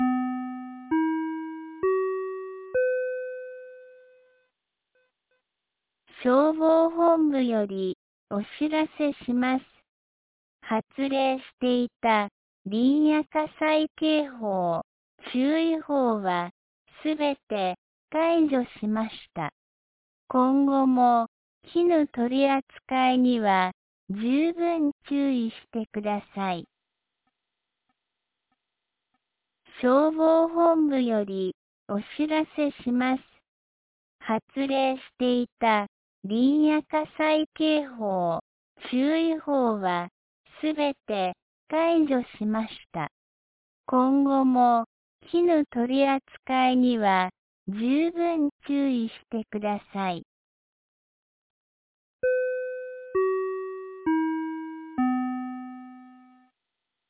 2026年02月09日 08時01分に、安芸市より全地区へ放送がありました。